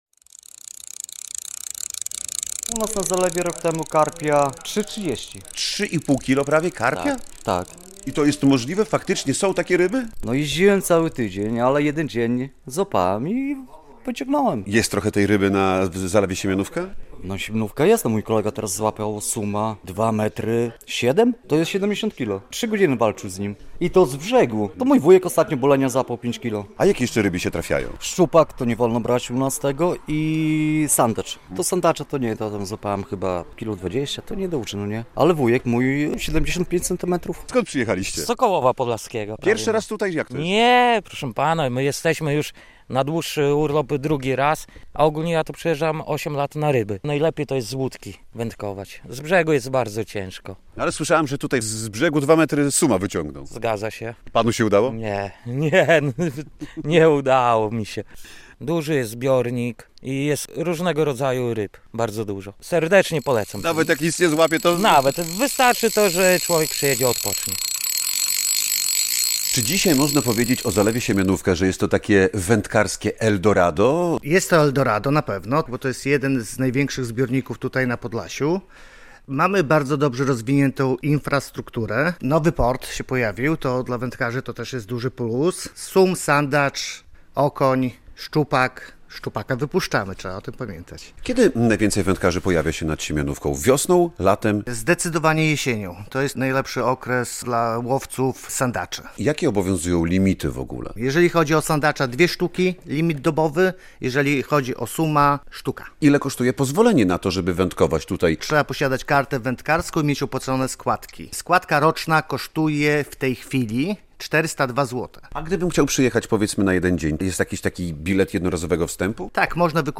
Jak się zmienia i rozwija plaża w Rudni - relacja